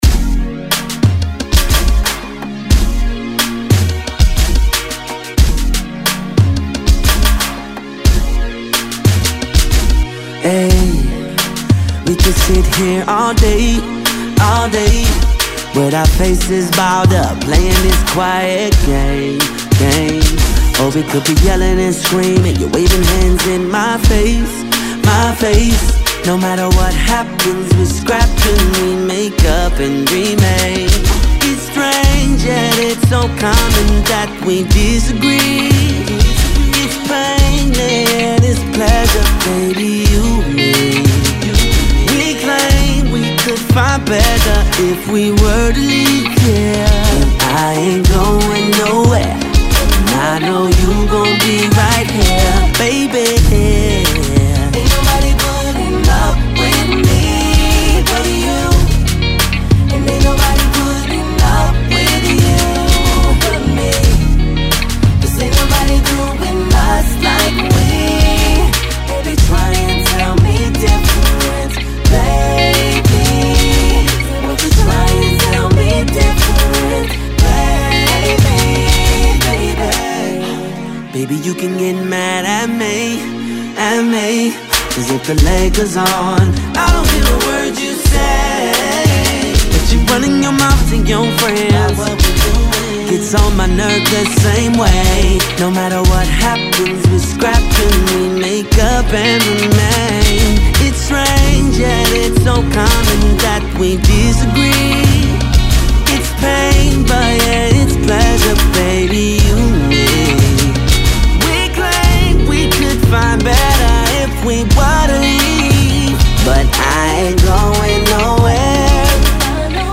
smooth, guitar-tinged production